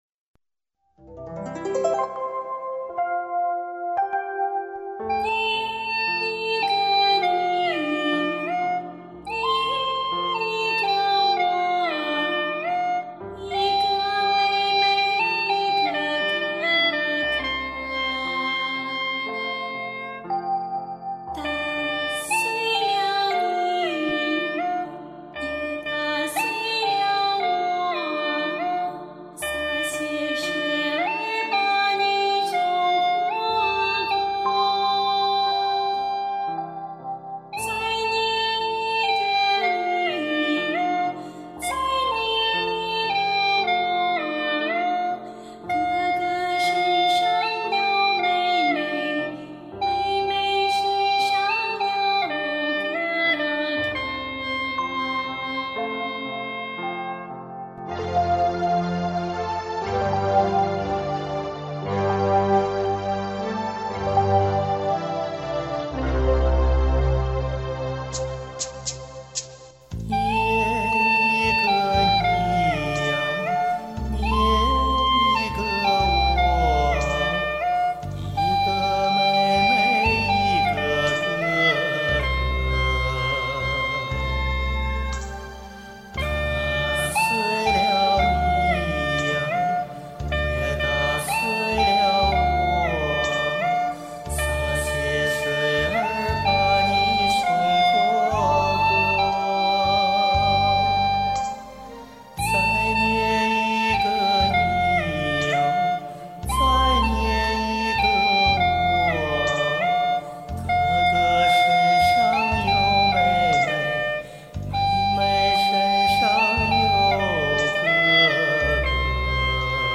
尝试跟着人声吹。
其实这曲子很好听的，因为没有伴奏和曲谱，我就跟着人家唱的调子吹（声明：这不是我唱的），这葫芦丝的声音好像“第三者”插足
另类的伴奏呀！
曲风比较淳朴，不能说是土的掉渣。